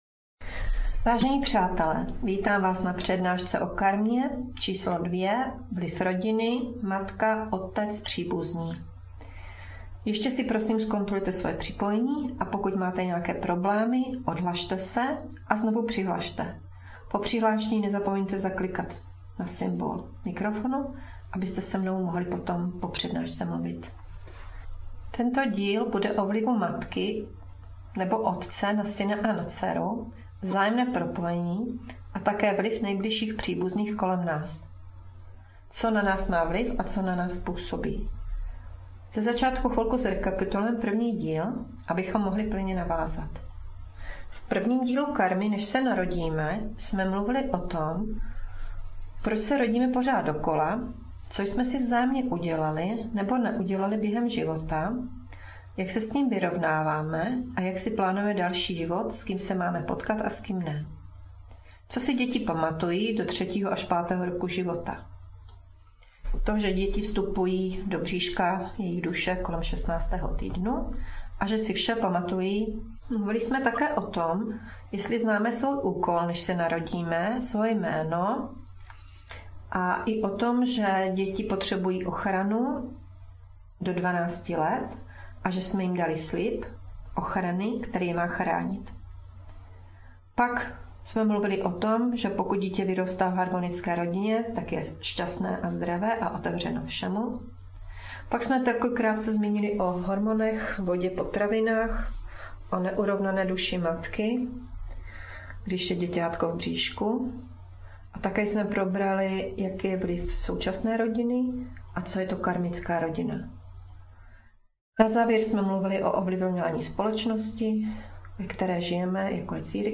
Přednáška Karma 2/8 - Vliv rodiny, matka - otec - příbuzní